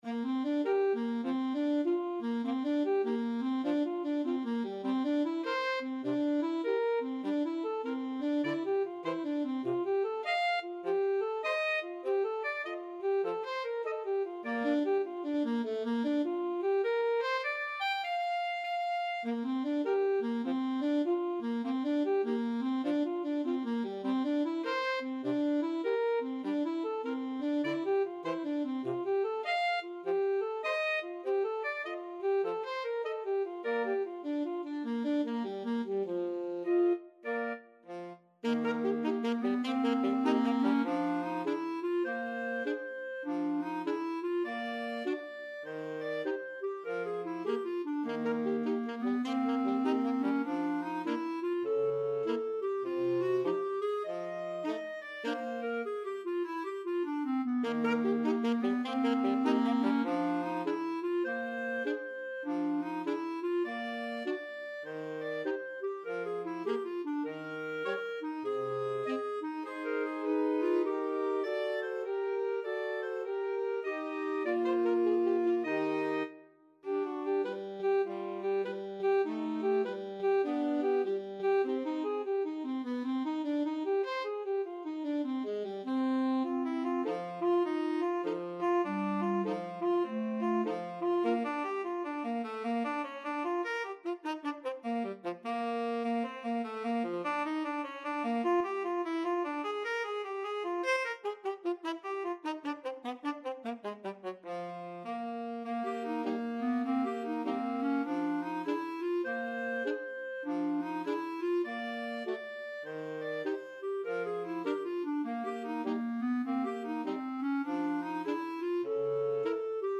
Alto Saxophone
Clarinet
Tenor Saxophone
4/4 (View more 4/4 Music)
Lightly = c. 100